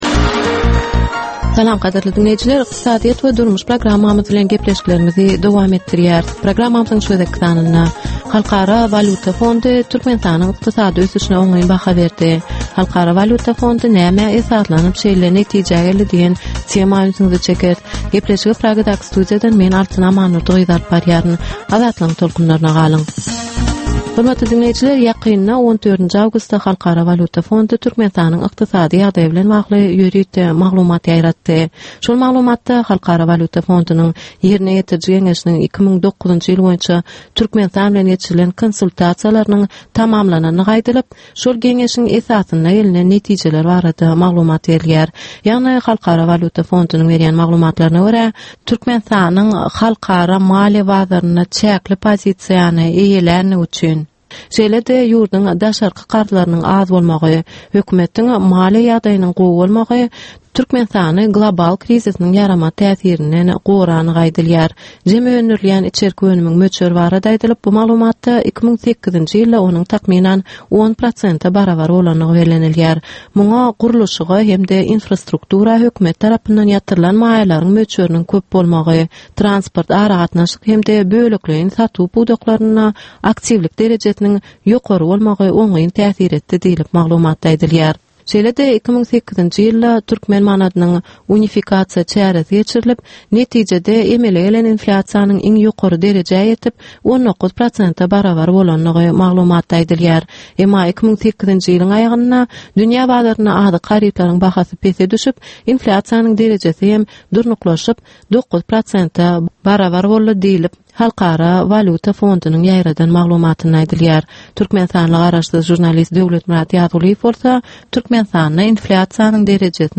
Türkmenistanyn ykdysadyýeti bilen baglanysykly möhüm meselelere bagyslanylyp taýýarlanylýan 10 minutlyk ýörite geplesik. Bu geplesikde Türkmenistanyn ykdysadyýeti bilen baglanysykly, seýle hem dasary ýurtlaryñ tejribeleri bilen baglanysykly derwaýys meseleler boýnça dürli maglumatlar, synlar, adaty dinleýjilerin, synçylaryn we bilermenlerin pikirleri, teklipleri berilýär.